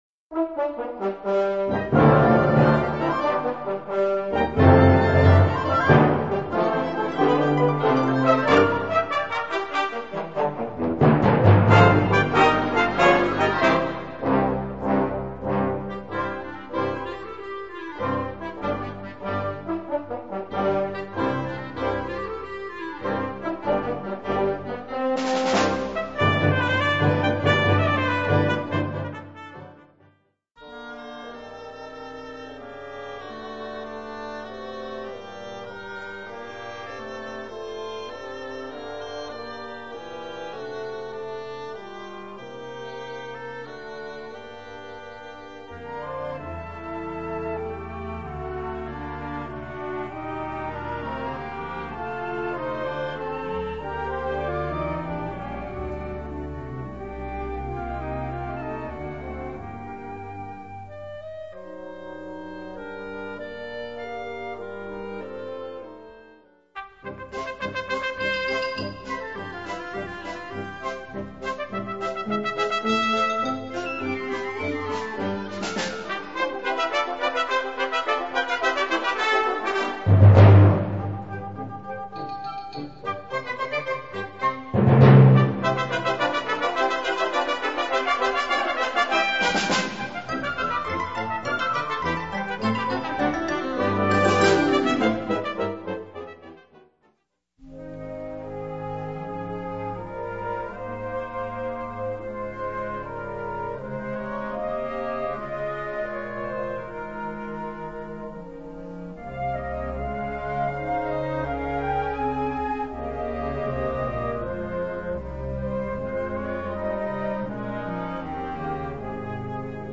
Catégorie Harmonie/Fanfare/Brass-band
Sous-catégorie Musique de concert, arrangement
Instrumentation Ha (orchestre d'harmonie)